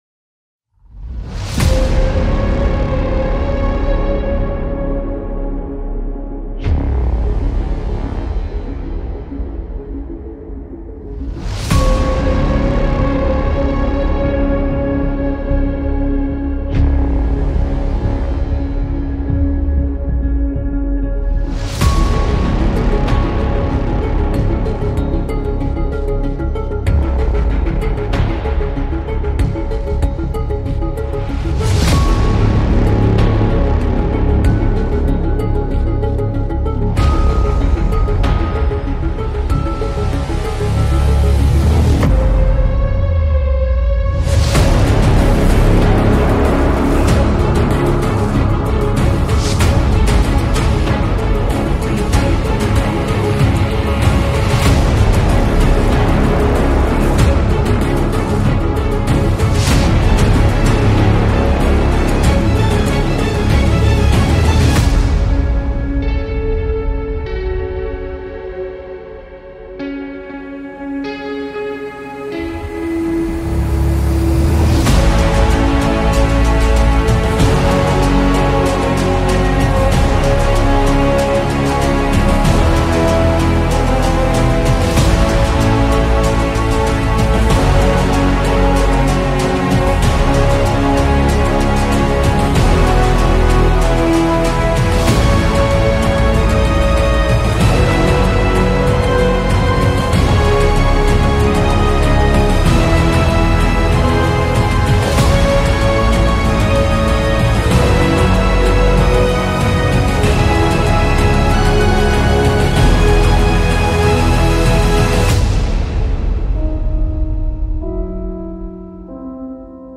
آهنگ بیس دار بی کلام ریتم تند بیس دار سنگین